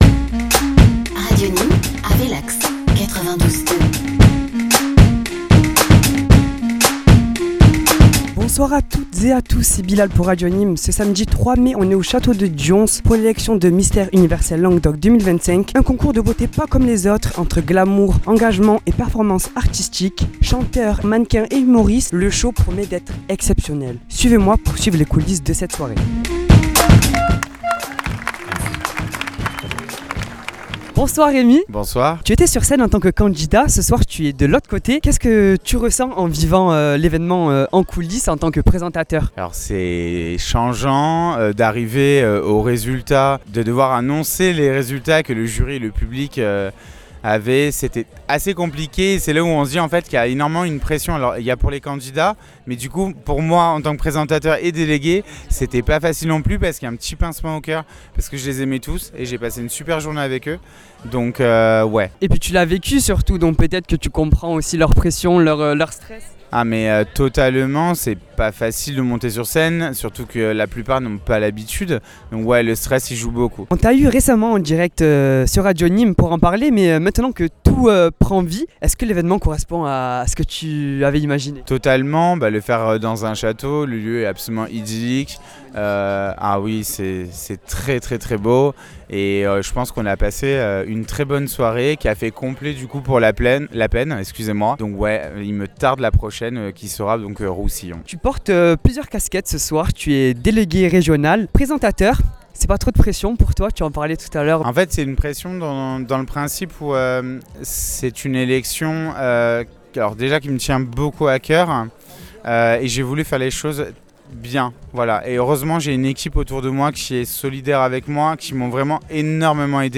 Retour sur la grande soirée d’élection de Mister Universel Languedoc 2025, organisée dans le cadre exceptionnel du Château de Dions. Ambiance, prestations, réactions à chaud : vous retrouverez les moments forts de l’événement, des candidats au jury, en passant par les partenaires.